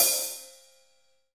HH HH283.wav